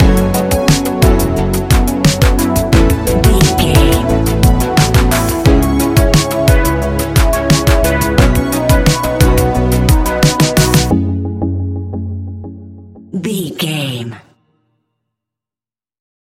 Ionian/Major
F♯
ambient
electronic
new age
chill out
downtempo
synth
pads
strings
space music